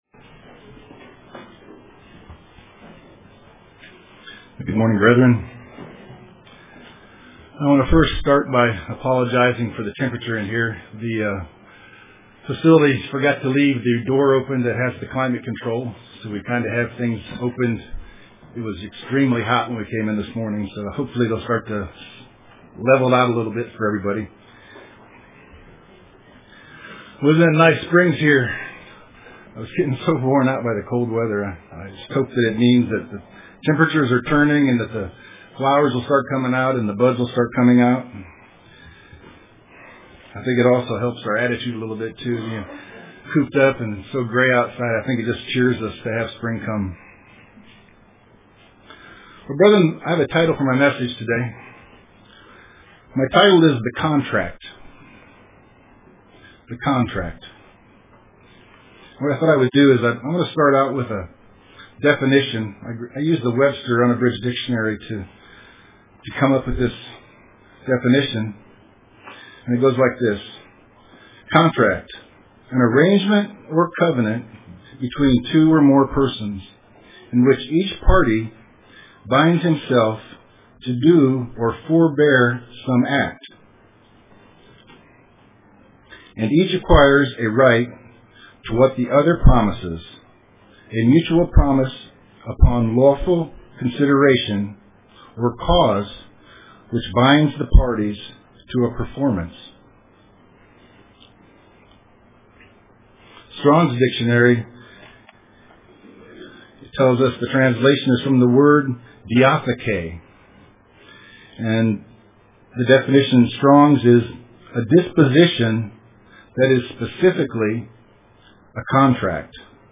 Print The Contract UCG Sermon Studying the bible?